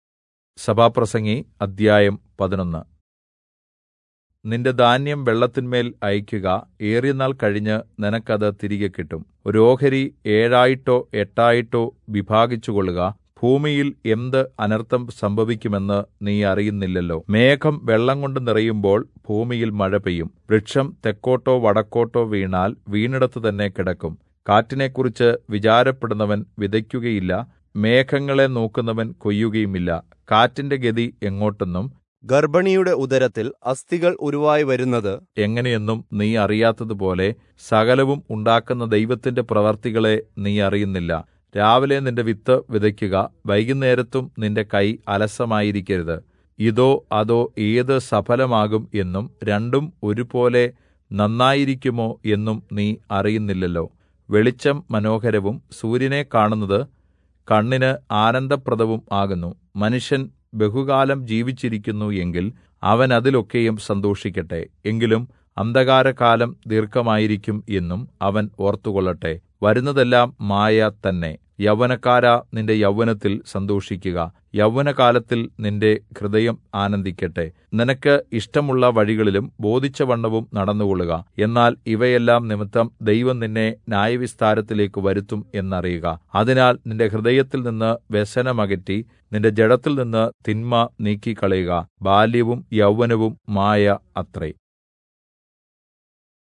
Malayalam Audio Bible - Ecclesiastes 7 in Irvml bible version